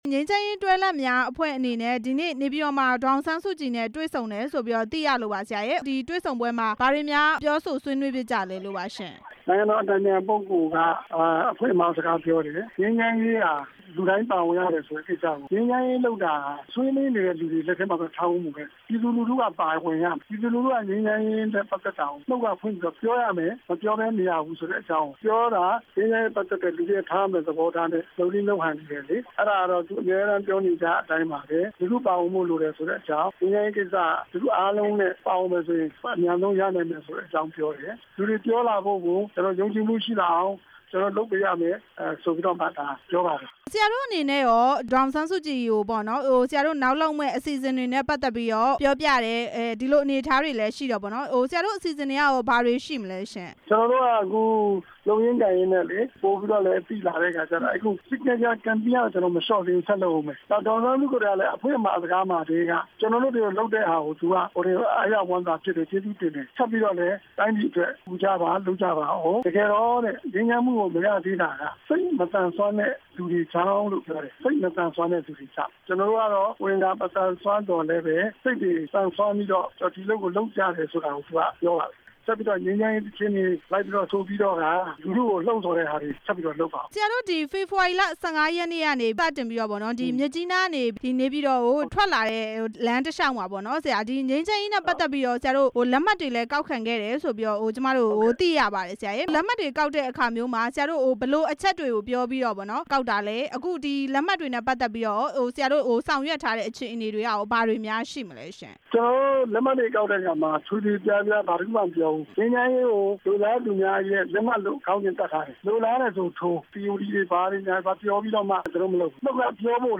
နေပြည်တော်ရောက် ငြိမ်းချမ်းရေးတွဲလက်များအဖွဲ့ နဲ့ မေးမြန်းချက်